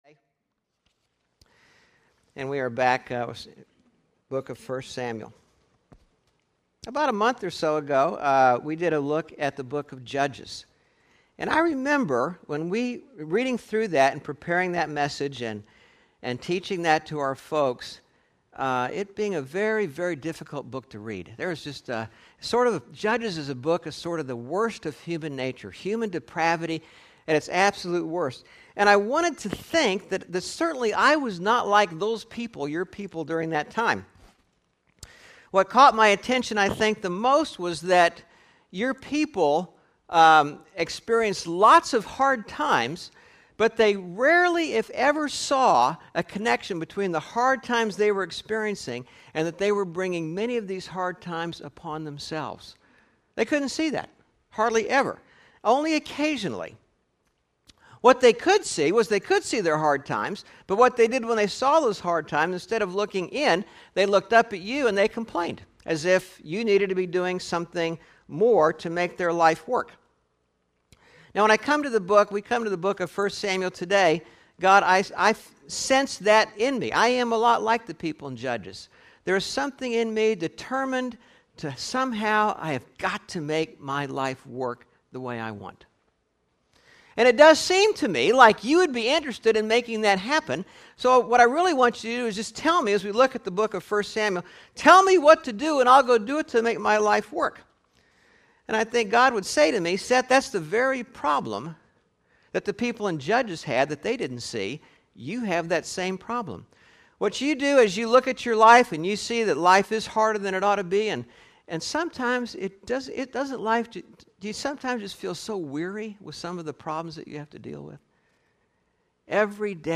8/7/11 Sermon (The book of 1 Samuel) – Churches in Irvine, CA – Pacific Church of Irvine